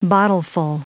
Prononciation du mot : bottleful
bottleful.wav